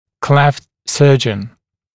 [kleft ‘sɜːʤən][клэфт ‘сё:джэн]хирург, выполняющий пластику расщелины губы и нёба